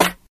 Snares
neptunessnareclap.wav